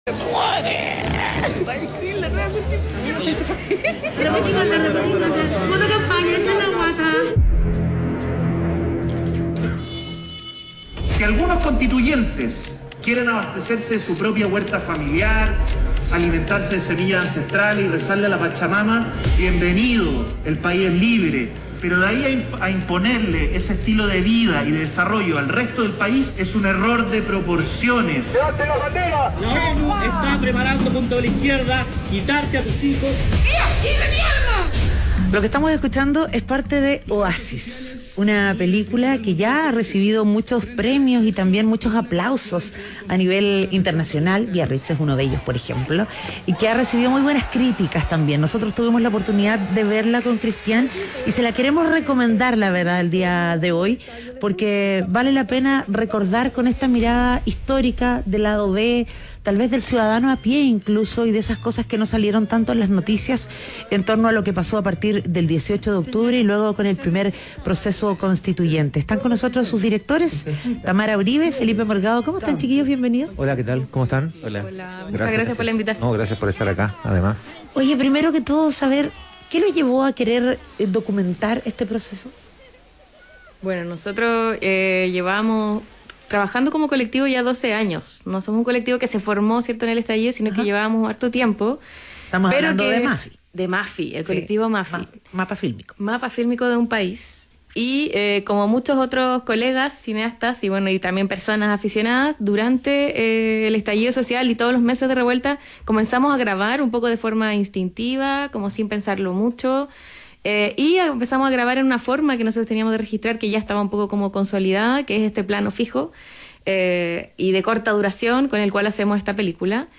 En conversación con Ciudadano ADN